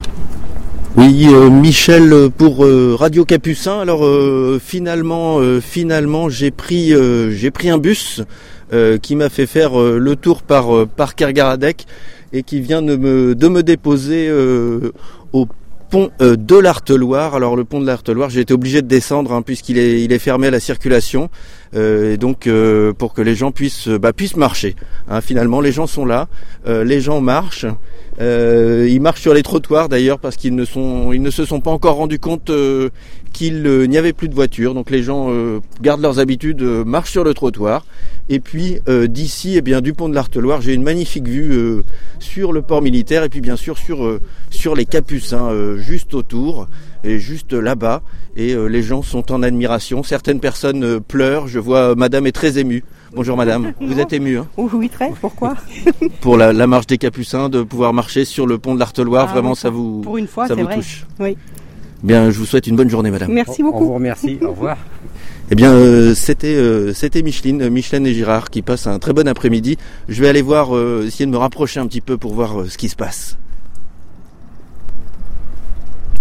Reportage officiel
En direct du pont de l’Harteloire